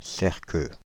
Cerqueux (French pronunciation: [sɛʁkø]
Fr-Paris--Cerqueux.ogg.mp3